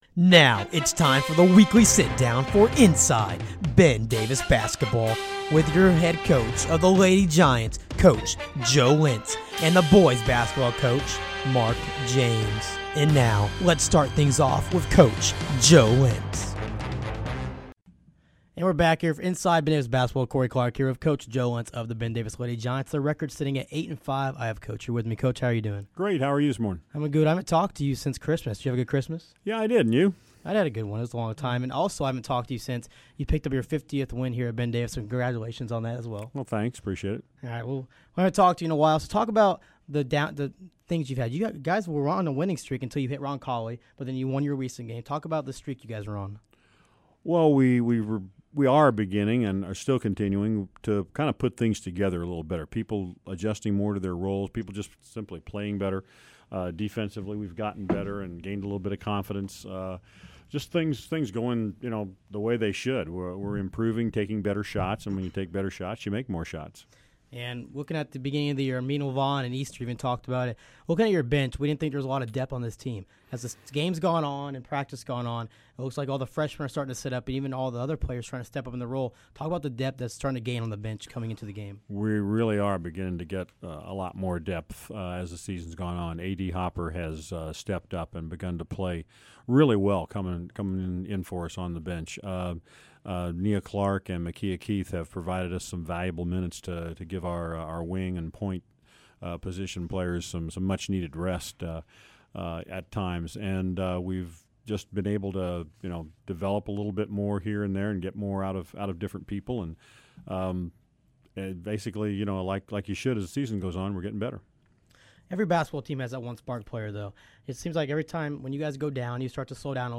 Sit downs with coaches